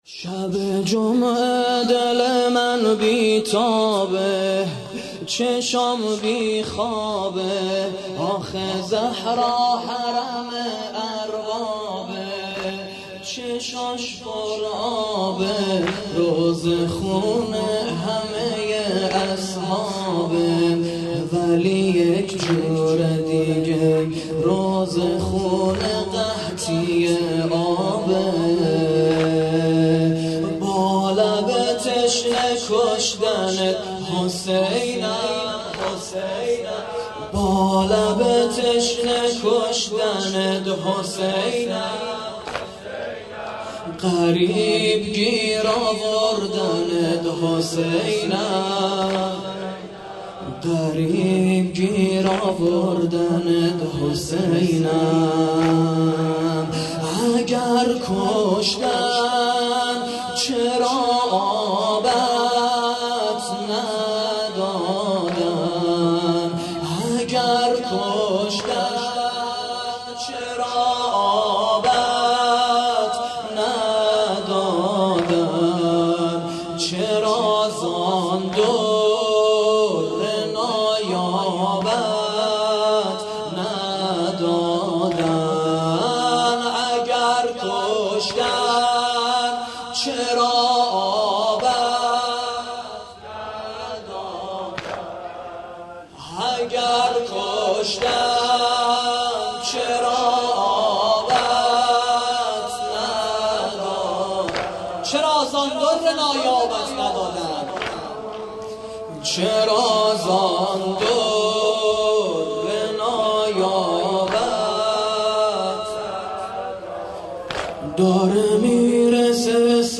واحد تند ( شب جمعه دل من بی تابه )
◼عزاداری دهه اول محرم - ۱۳۹۹/۶/۷